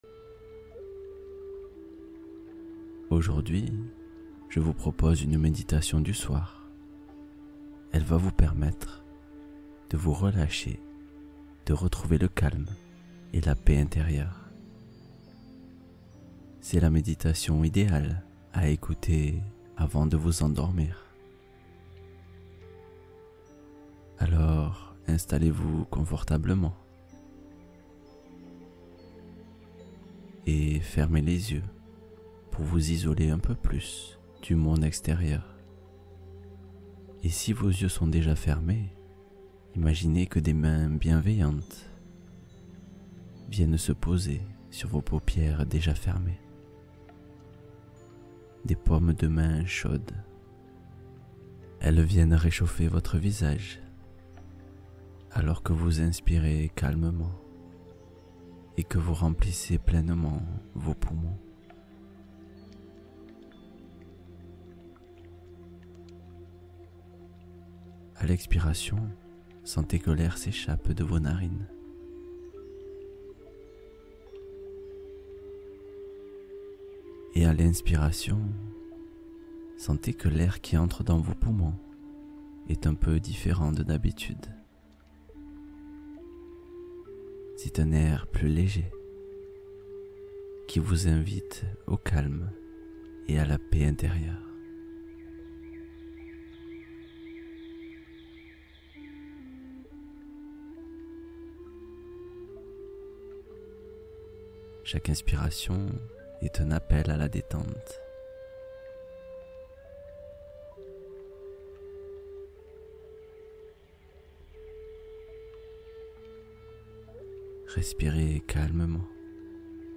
Sommeil profond et réparat Confiance et lâcher prise : méditation guidée avec affirmations positiveseur : voyage apaisant au fil de l’eau